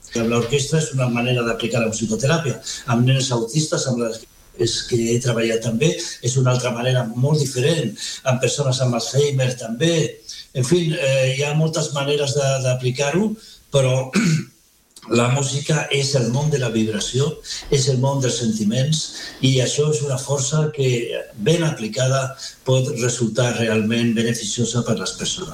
Aquest dijous hem conversat amb ell a l’Entrevista del dia del matinal de RCT, on ha recordat els orígens del projecte i una experiència especialment colpidora que exemplifica el poder de la música.